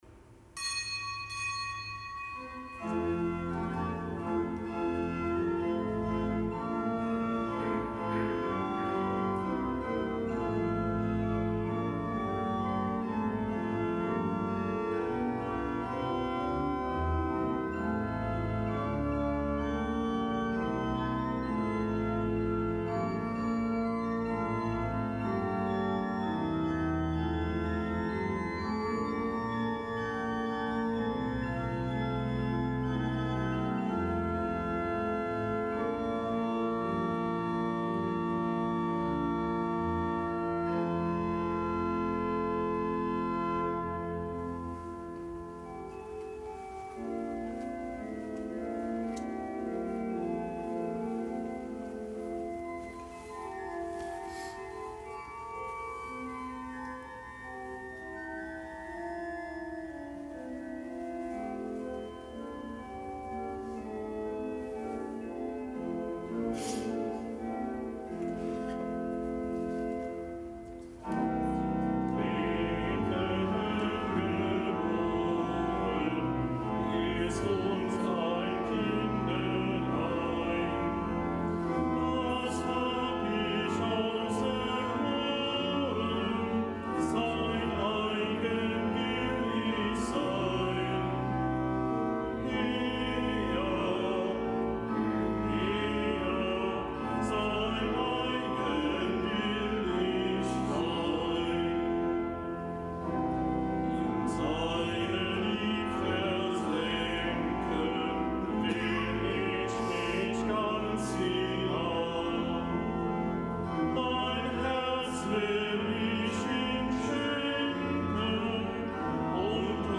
Gottesdienst
Kapitelsmesse aus dem Kölner Dom am 6. Tag der Weihnachtsoktav.